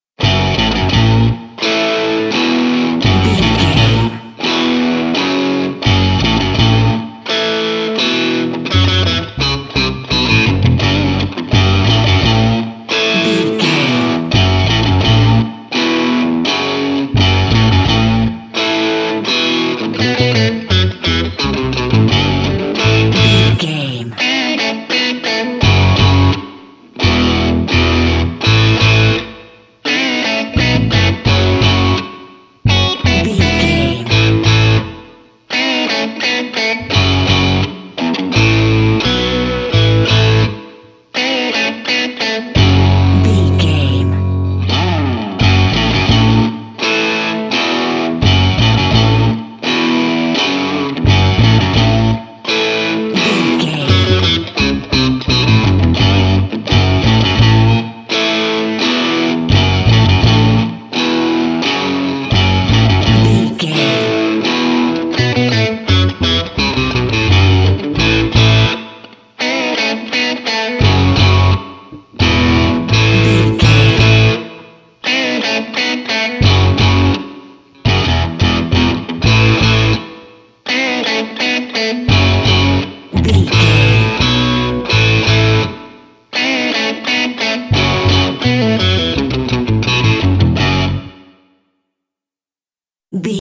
Epic / Action
Uplifting
Aeolian/Minor
F♯
bass guitar
electric guitar
drum machine
aggressive
intense
driving
heavy